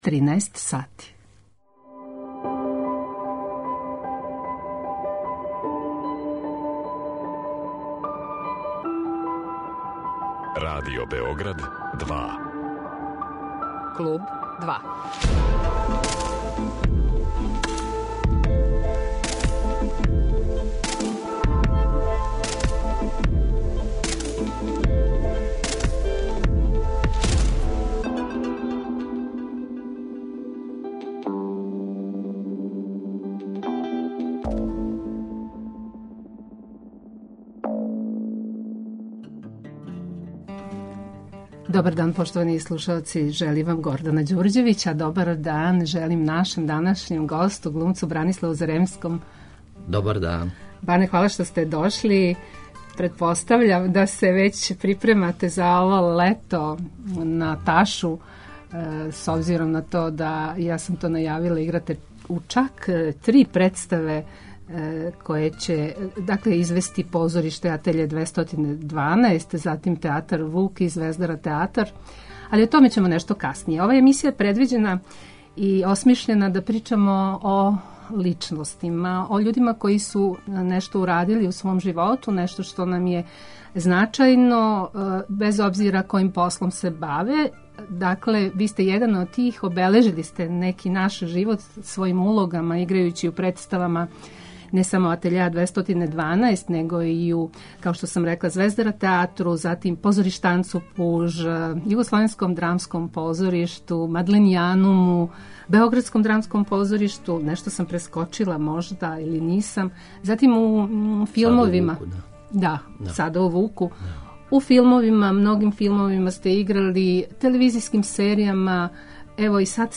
Гост Клуба 2 је глумац